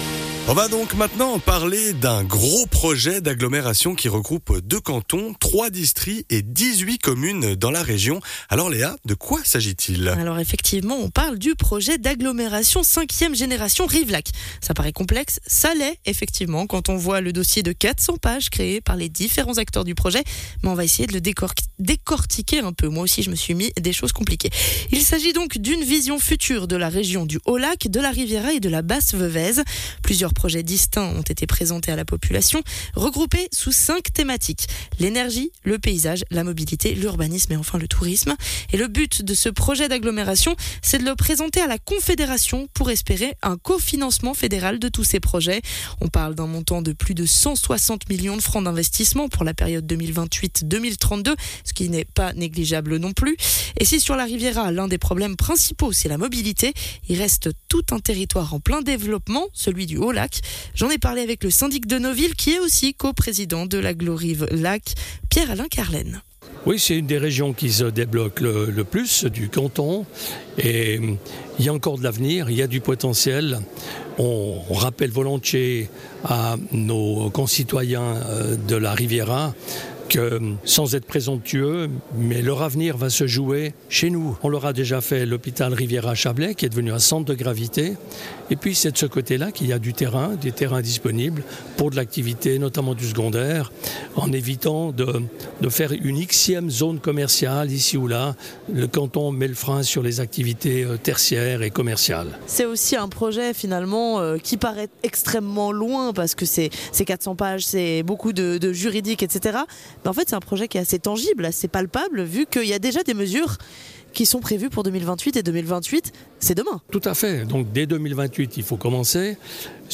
Intervenant : Pierre-Alain Karlen, syndic de Noville et co-président d'Agglo Rivelac